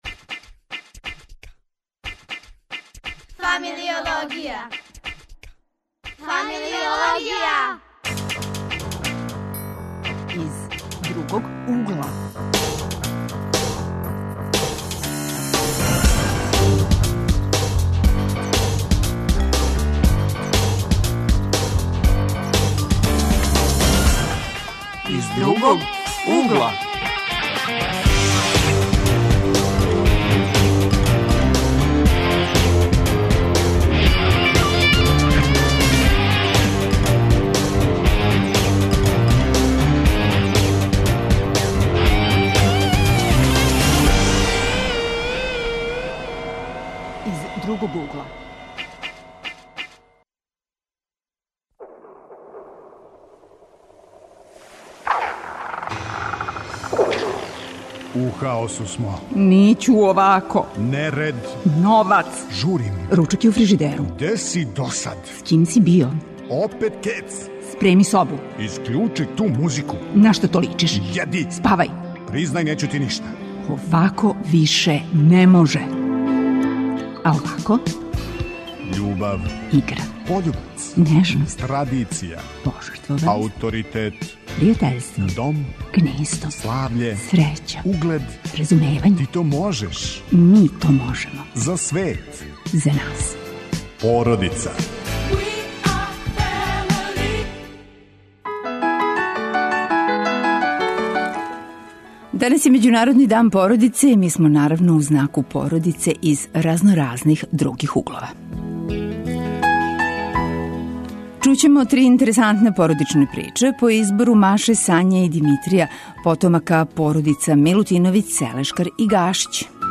Укључићемо се у ''породичне игре'', које се традиционално, поводом међународног дана породице, играју у основној школи у селу Бождаревац.
Гости смисије су студенти и средњошколци.